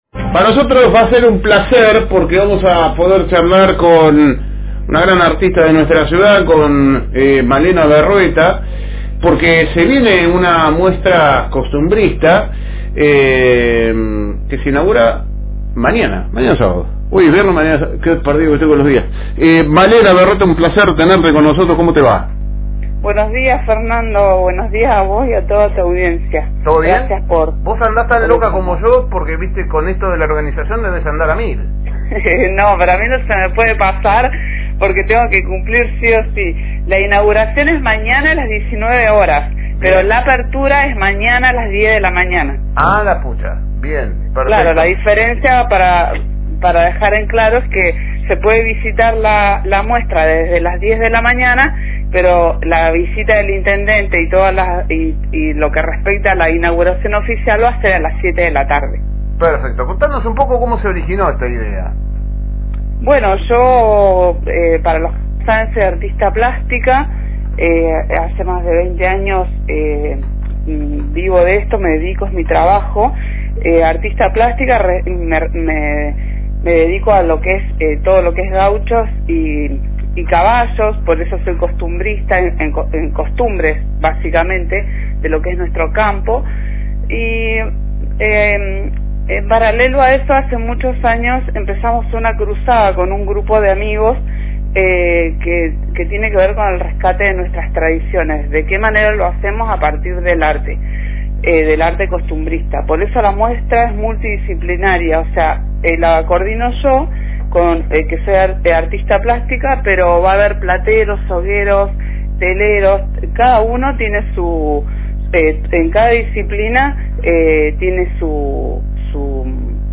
Tuvimos la oportunidad de conversar